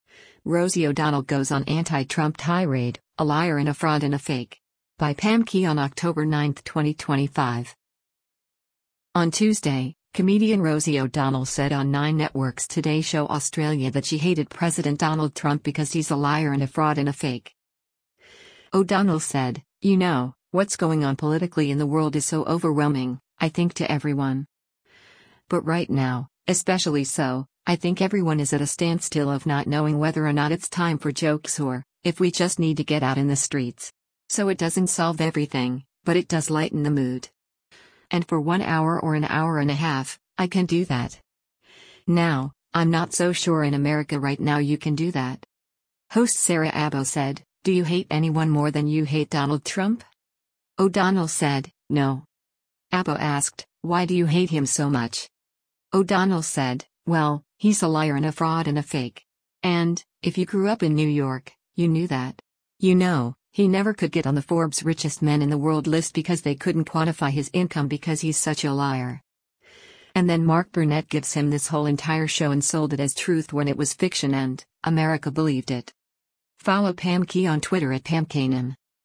On Tuesday, comedian Rosie O’Donnell said on Nine Network’s “Today Show Australia” that she hated President Donald Trump because “he’s a liar and a fraud and a fake.”